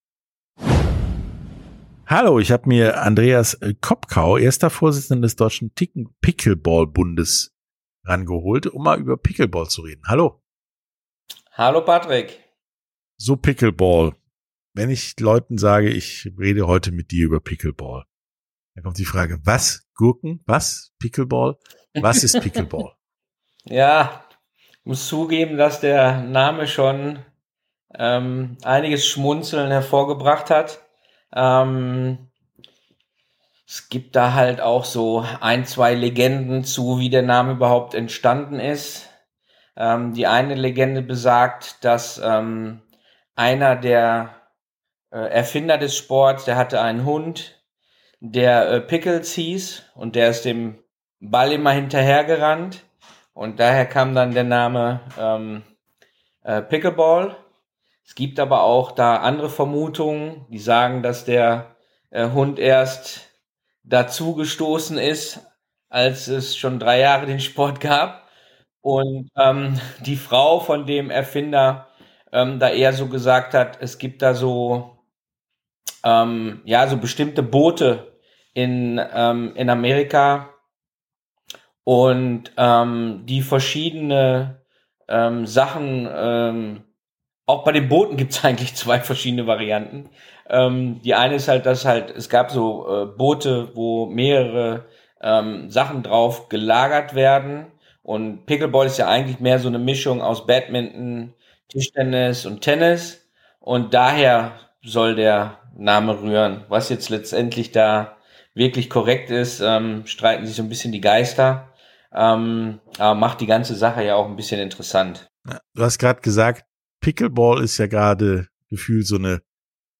hier das Interview mit Pickleball.